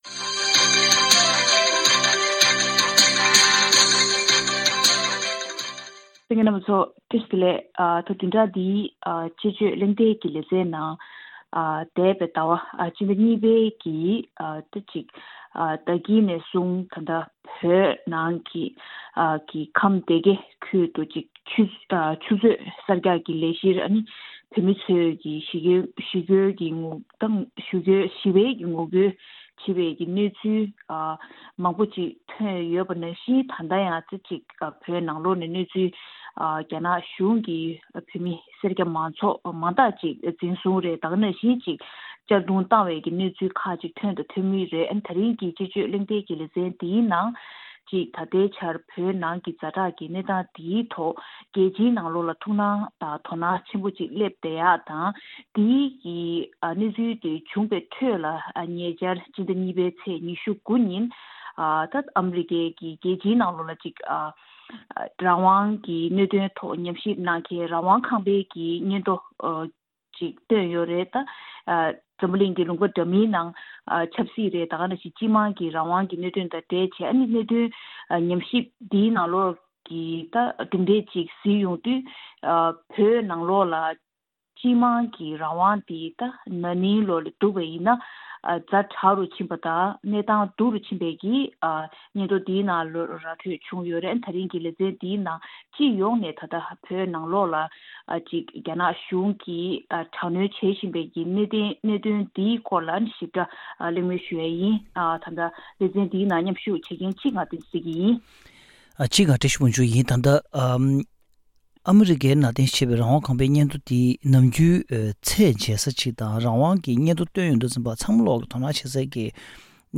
དཔྱད་གཞིའི་གླེང་མོལ